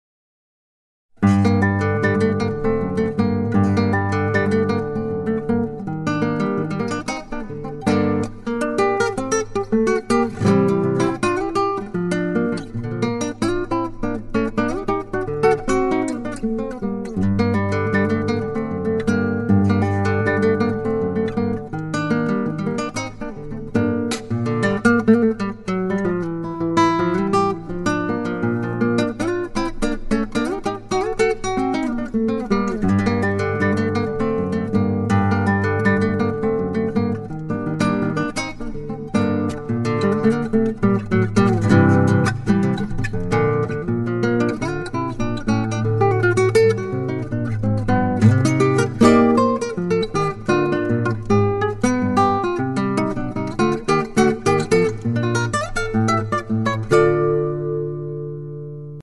chacarera